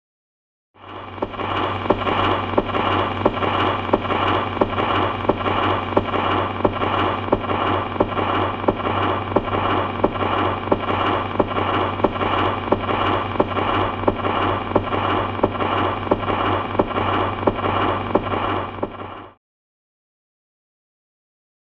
Record Player; Record Needle Running In Groove.